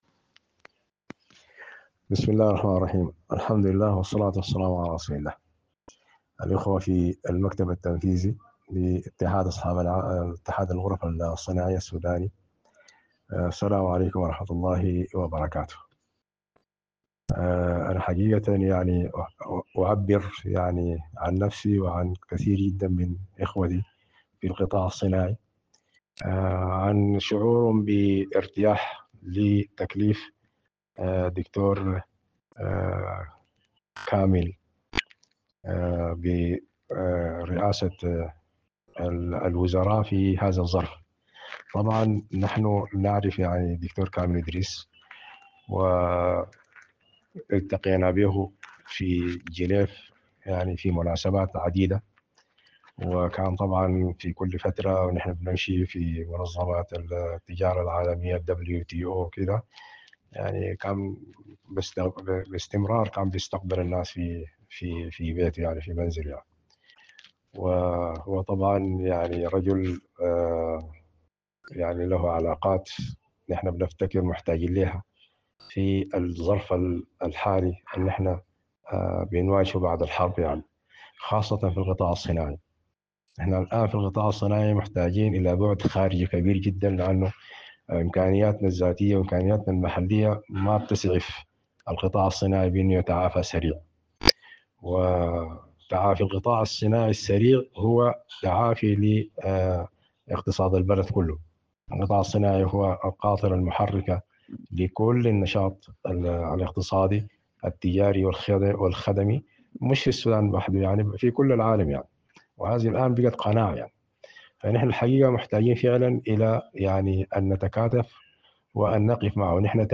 كلمة